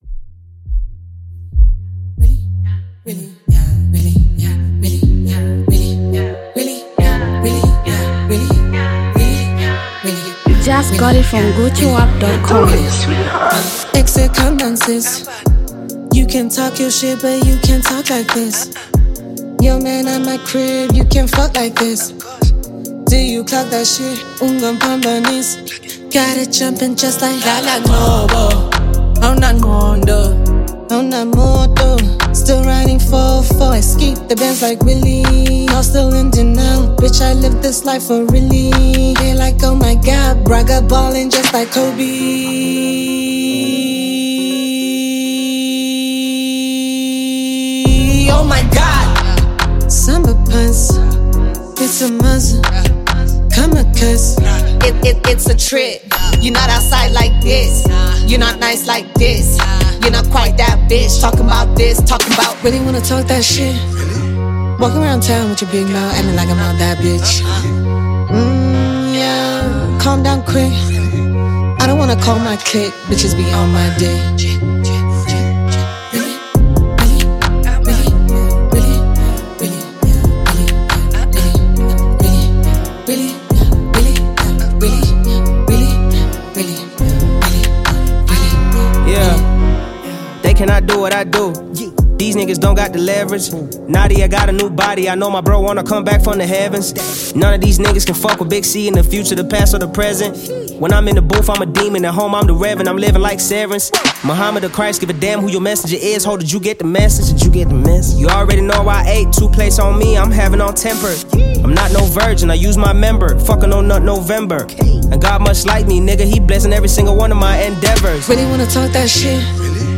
Two heavyweight rappers
street anthem